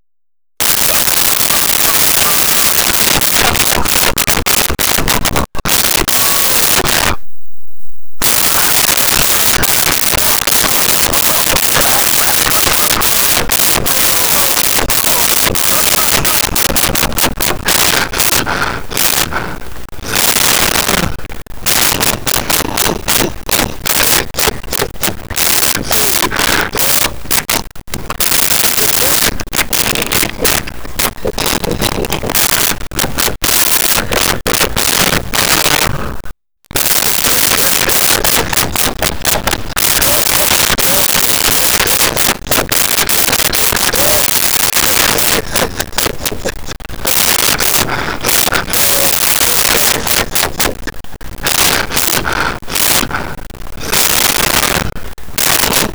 Laughing Male 01
Laughing Male 01.wav